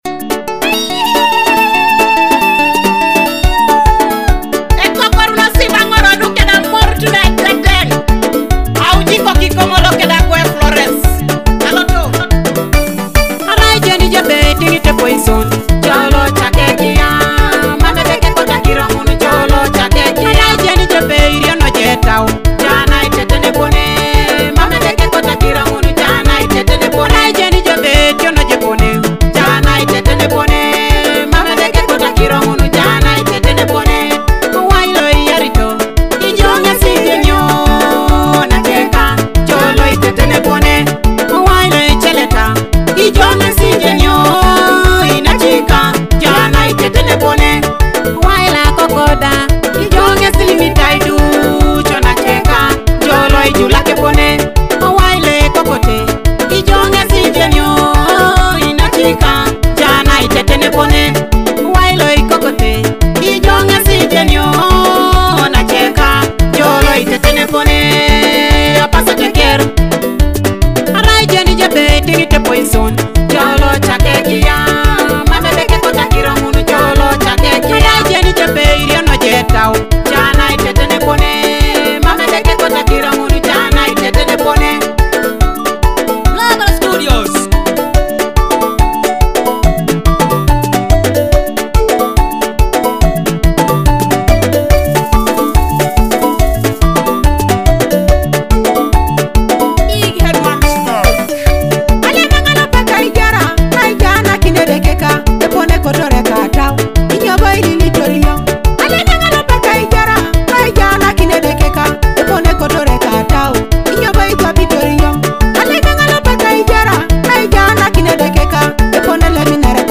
With its traditional beats blended with contemporary music